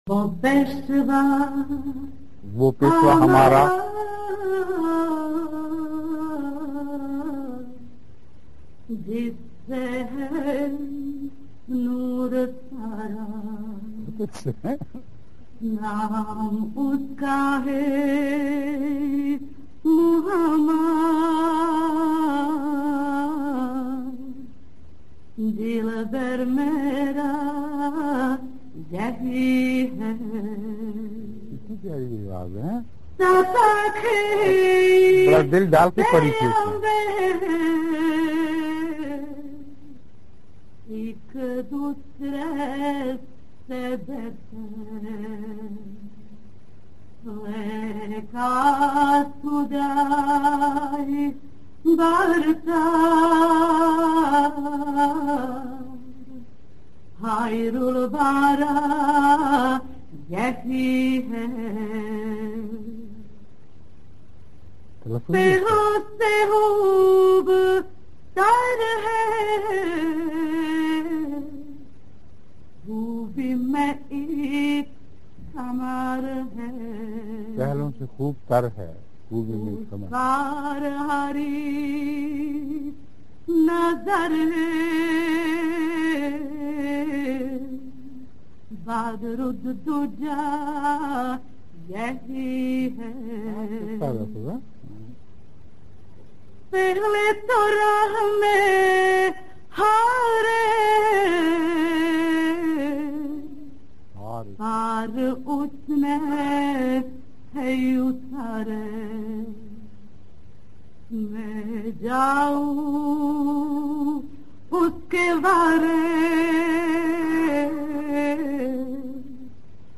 آواز: اردو کلاس
Voice: Urdu Class